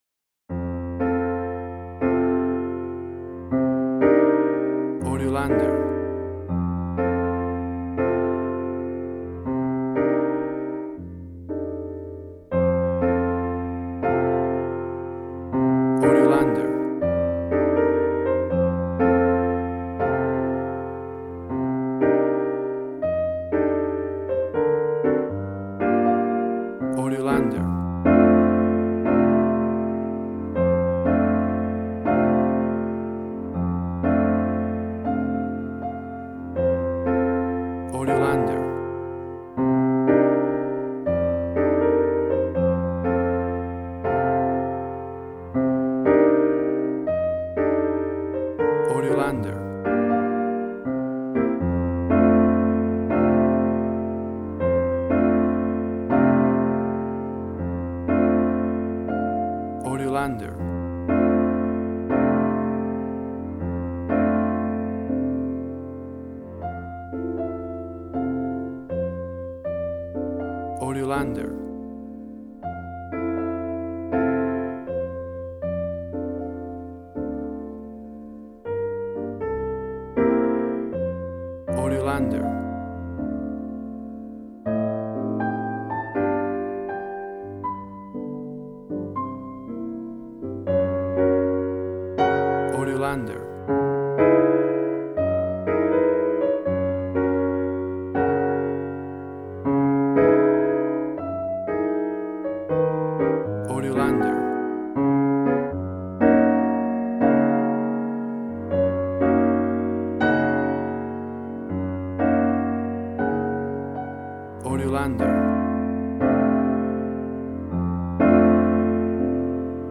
Smooth jazz piano mixed with jazz bass and cool jazz drums.
Tempo (BPM): 80